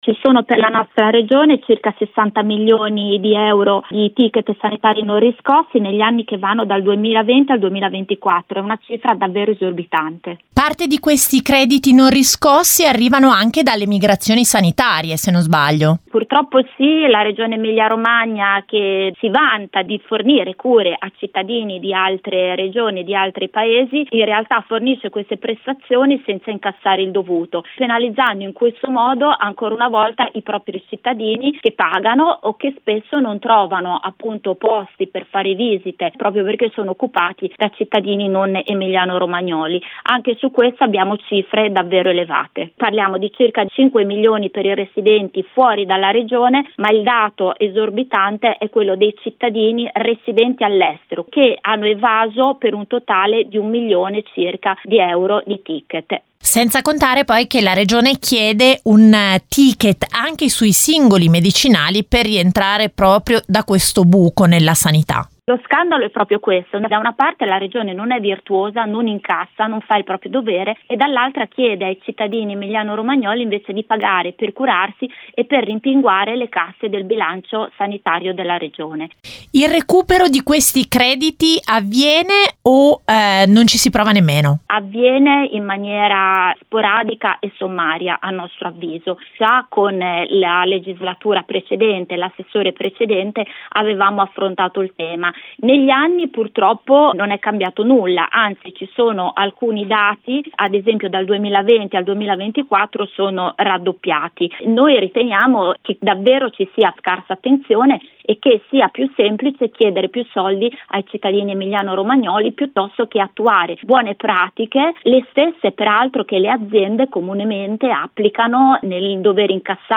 L’intervistata